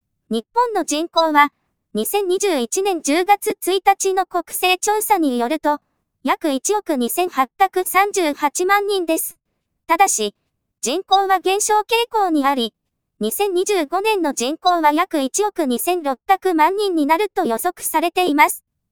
Ubuntuでテキスト音声出力実験
Linuxで合成音声をバッチ作成する方法は色々あるけれどopen_jtalkで実験してみた。
わりと許せる音声になってますね。